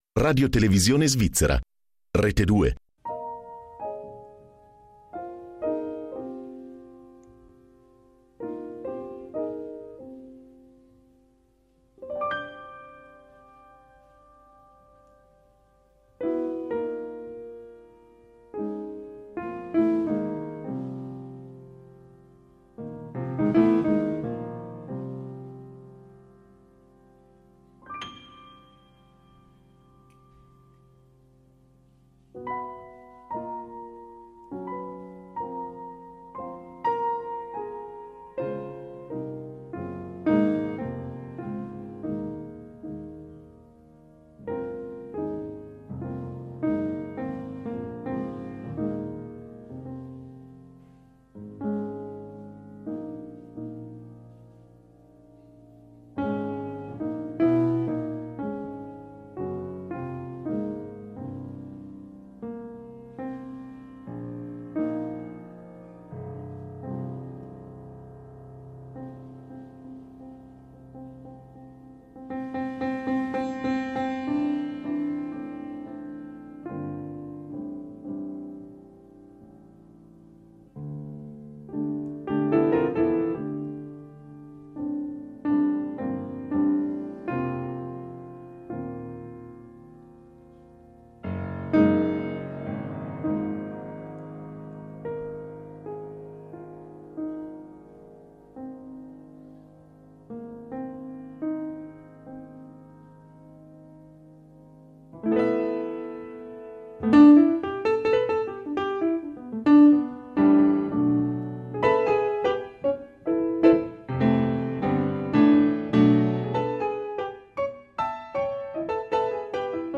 In diretta da Jazz in Bess - Lugano